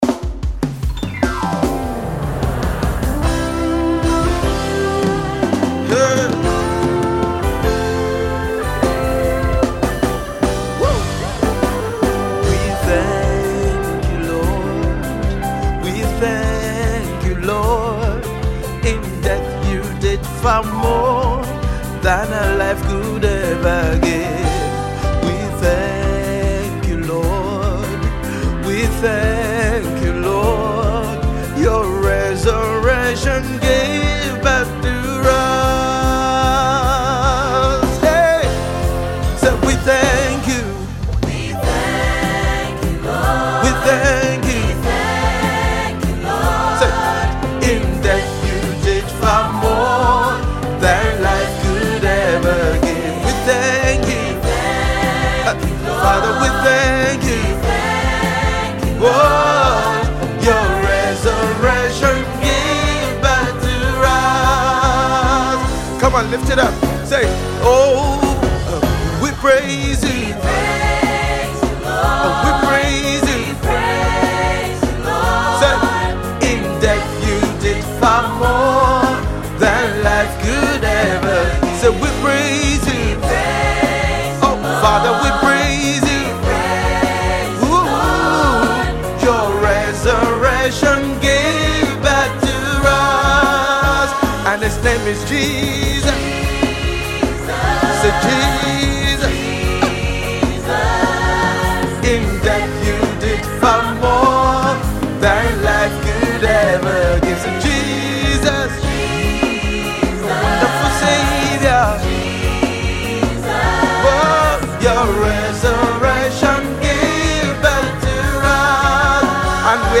praise track